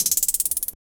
pluggnbtick.wav